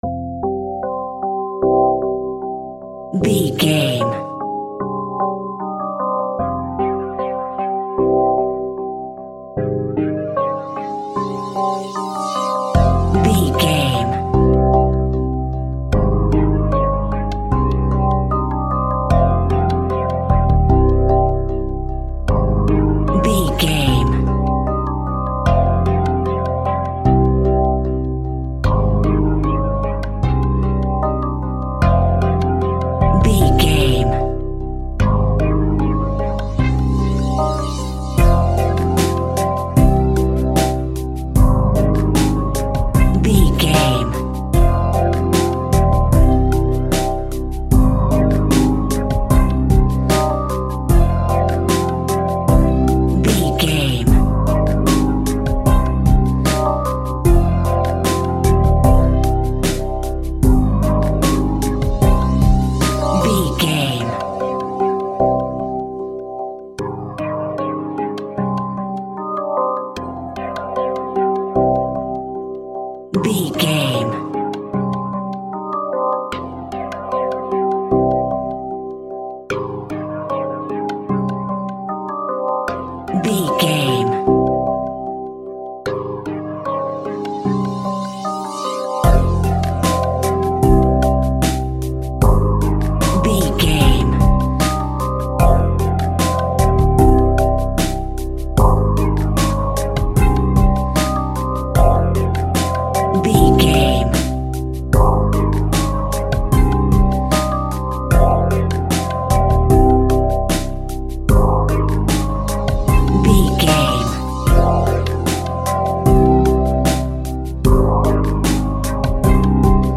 Smooth and Chilling Rap.
Aeolian/Minor
Slow
hip hop
laid back
hip hop drums
hip hop synths
piano
hip hop pads